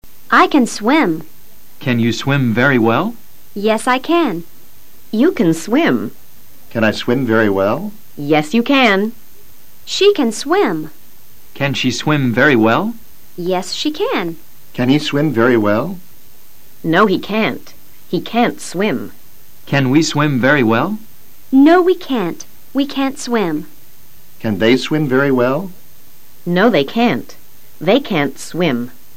Y ahora escucha a los profesores leyendo oraciones con el verbo "CAN".